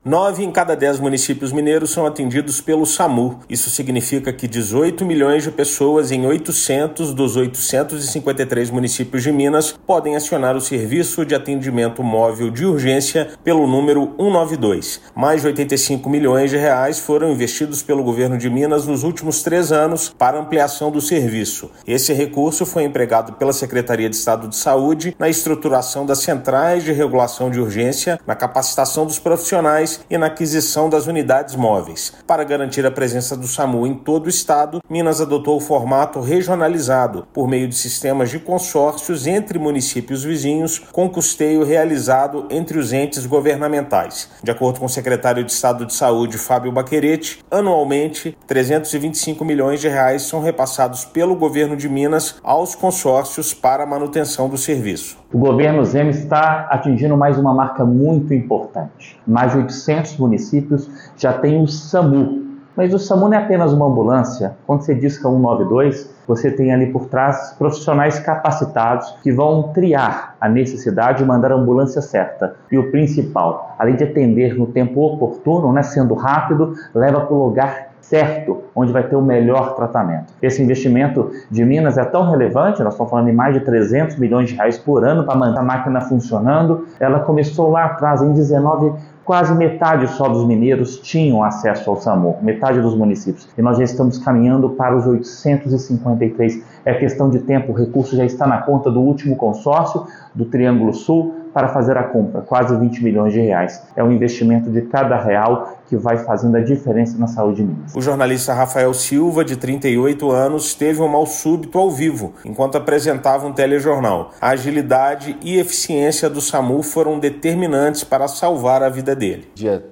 Governo de Minas investiu mais de R$ 85 milhões na ampliação do serviço nos últimos três anos e cerca de R$ 325 milhões anuais para o custeio. Ouça matéria de rádio.